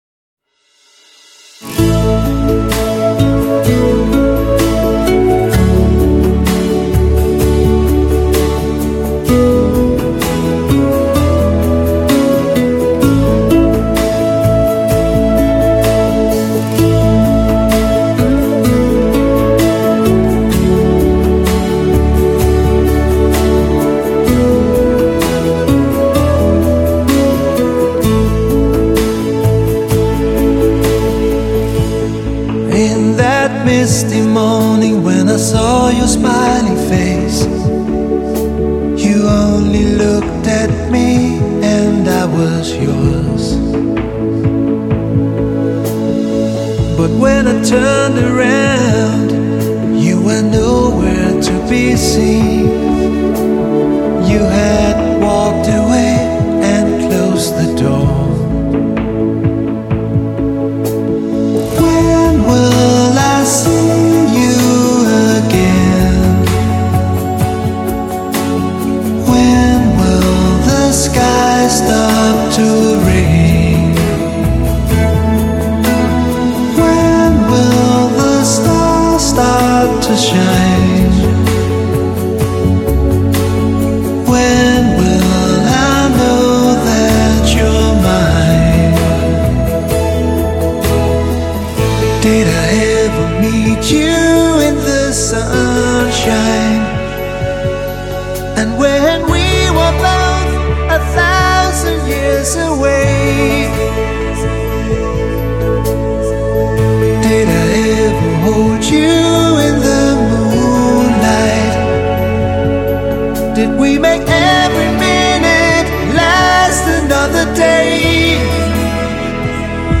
歌曲充满了浪漫的风味和气息，让人感觉意犹未尽。